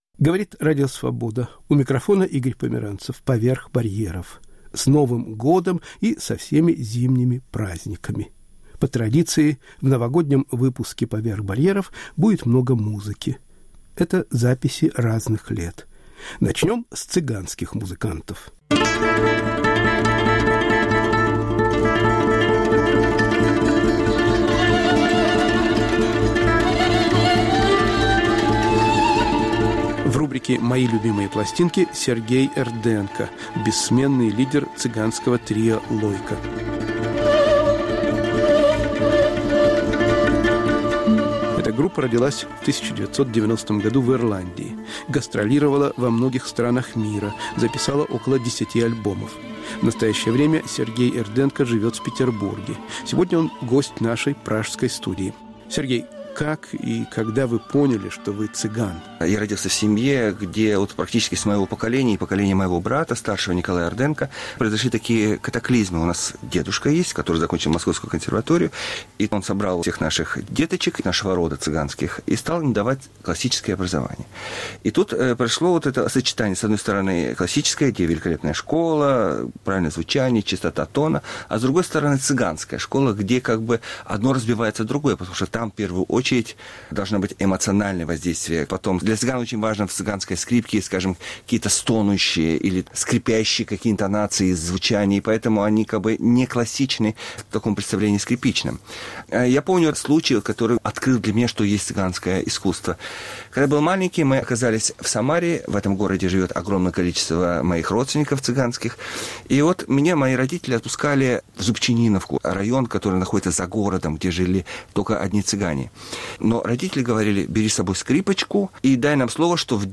Рассказывают и поют Ирма Сохадзе, цыганское трио "Лойко", поэт и музыкант Лев Рубинштейн.